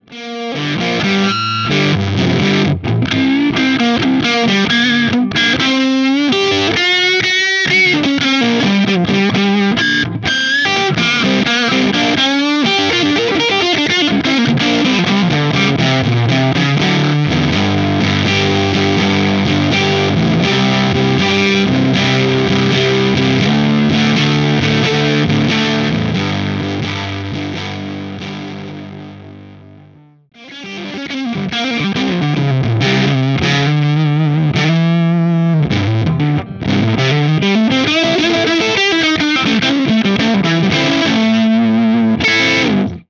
Totally unstructured, free form clips!
With the JTM I used my TTA Trinity cab, the Marshall with Greenbacks, and the Mesa Recto with V-30s.
I set up 2 mics this time – a Shure SM57 and an Audix i5. The i5 has a more pronounced top end and bigger bottom and compliments the 57’s midrange thing really well.
K_JTM5_Input1Hi_Cyclone_Neck_MesaV30.mp3